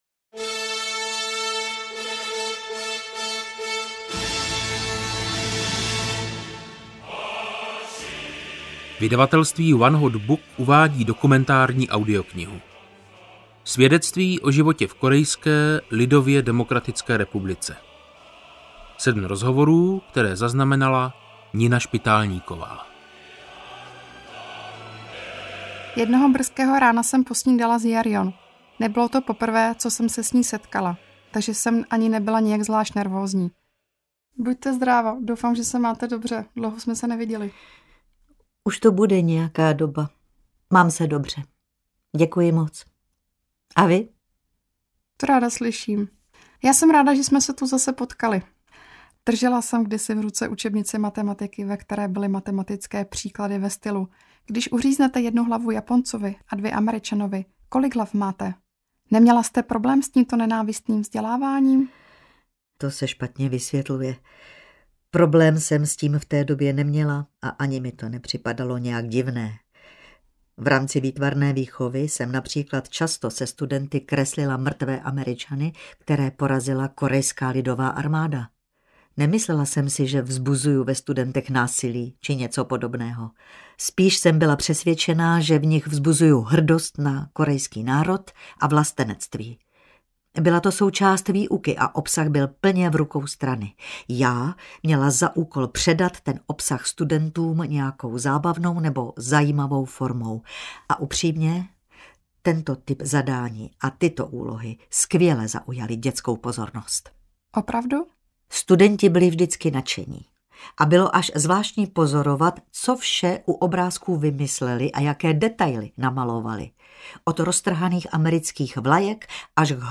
Svědectví o životě v KLDR audiokniha
Ukázka z knihy